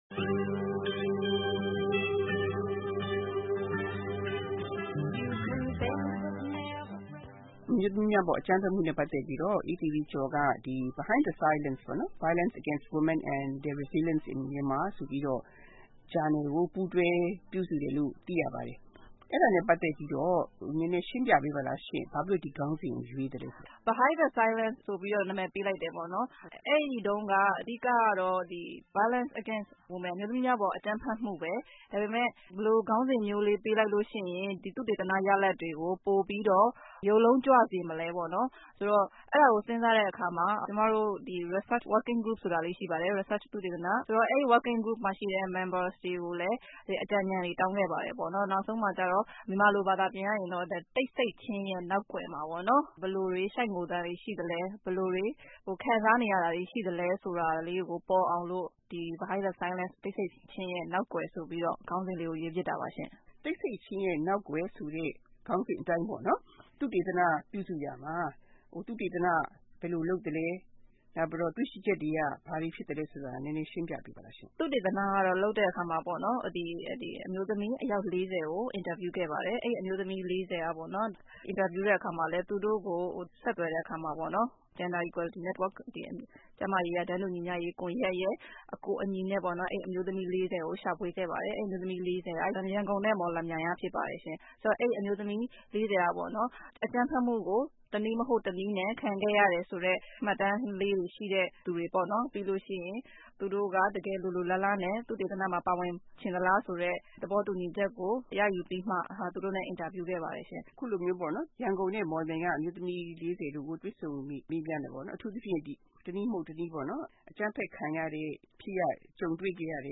ဗွီအိုအေ မြန်မာပိုင်းက တွေ့ဆုံမေးမြန်းခဲ့တဲ့